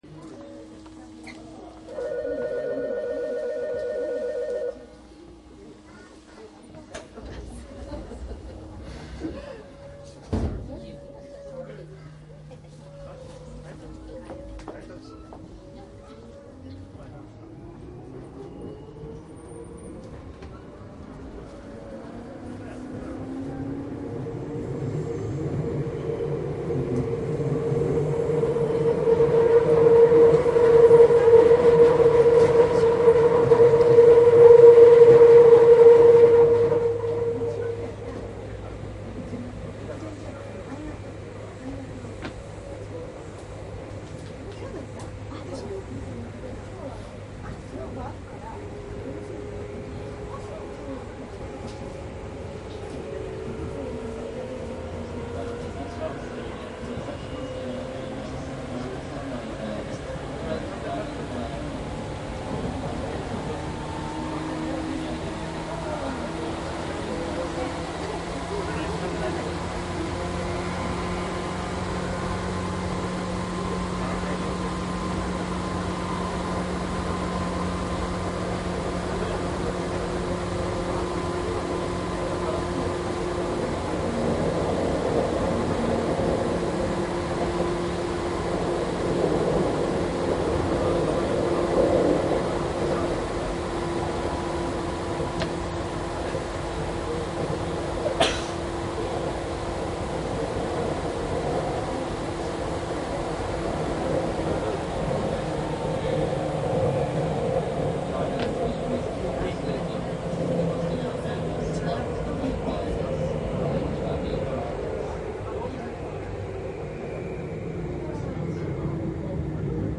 ♪鉄道走行音CD★東急東横線（旧線） 8000系 ★
商品説明東急電鉄８０００系 チョッパ制御
渋谷・桜木町と旧線時代の録音です。
下りは低音チョッパ にて、上りは通常の高音タイプ（TKM80？）です。
残念ながらどちらも神奈川県内で乗車率が高く、上りは客の会話が目立つ場面があります。
① 東急８０００系 （デハ８155） 東急東横線 （急行）渋谷→桜木町
注意事項収録機材は、ソニーDATと収録マイクソニーECM959を使用.。